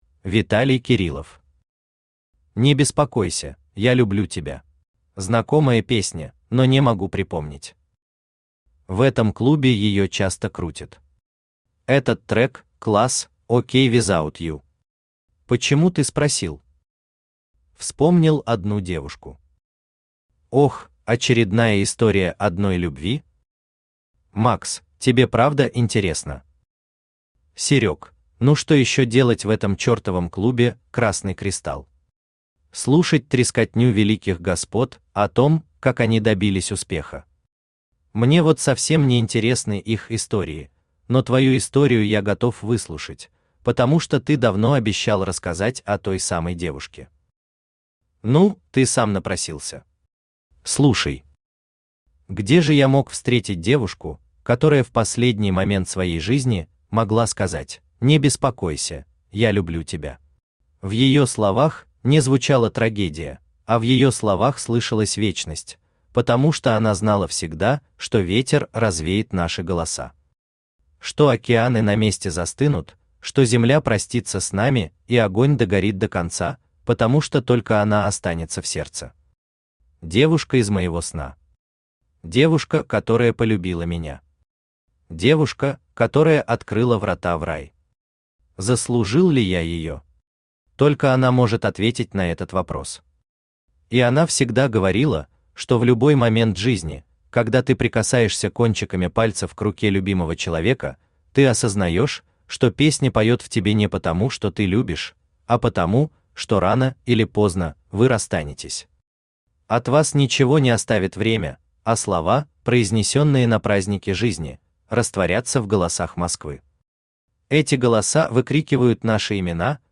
Аудиокнига Не беспокойся, я люблю тебя…
Автор Виталий Александрович Кириллов Читает аудиокнигу Авточтец ЛитРес.